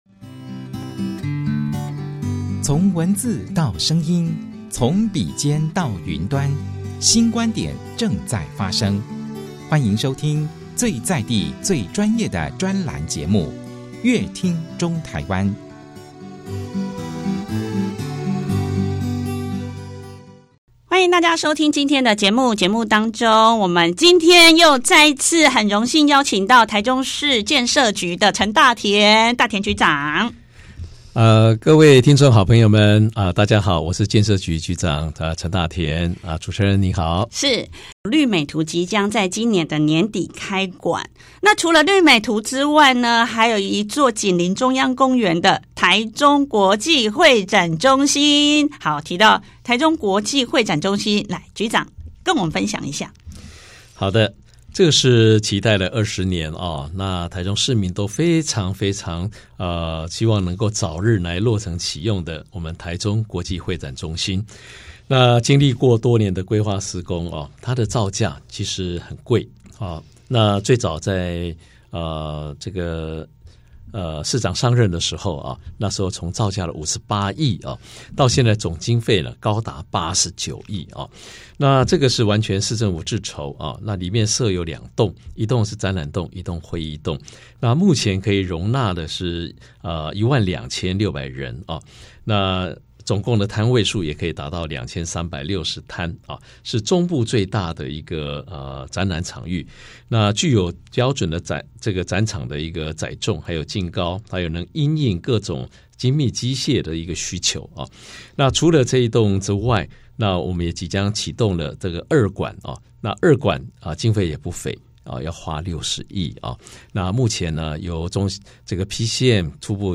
融入日常的城市美學 陳大田局長在節目中開門見山先跟大家宣布台中國際會展中心成為中部最大展覽場域，接著跟大家分享城市美學如何融入市政建設，他津津樂道說明市府團隊透過多面向的研究與規劃，並適度攜手民間參與設計，讓各項建設讓城市美學更貼近生活，更具生命力。提到台中市的親子小白宮是市府攜手台灣設計研究院共同設計，並透過多方溝通與協調，建立公部門與民間團體的共識，以此打造出美觀與實用兼具的親子小白宮。
他補充說觀音亭休憩平台整體設計以低彩度色調融入自然環境，並強化綠意氛圍與生態多樣性，想知道更多專訪內容，請鎖定本集節目。